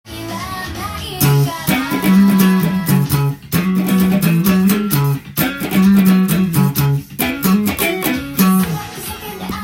ベースソロをギターtab譜にしました
普通の速さで譜面通りに弾いてみました
ギターで弾く場合は、カッティング奏法で弾くと同じような
スケールはC♯マイナーペンタトニックスケールを使っているようで